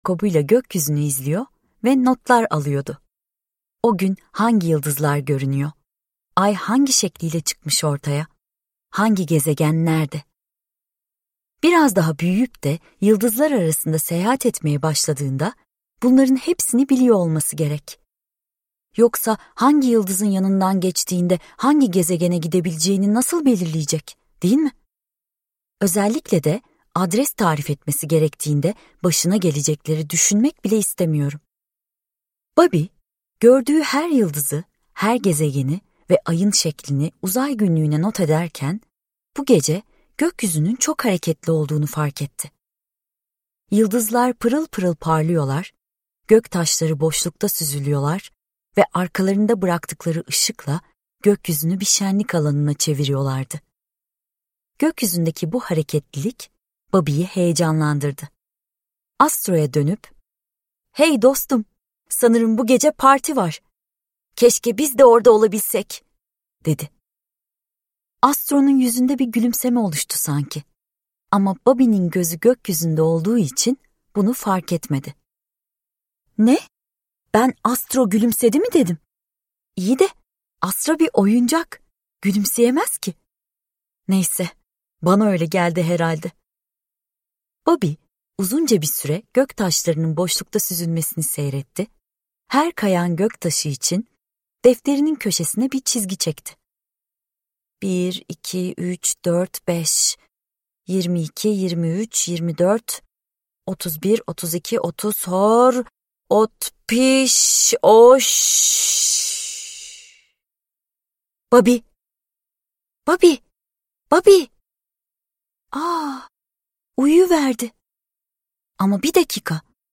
Astro ve Babi Aya Tırmanıyor - Seslenen Kitap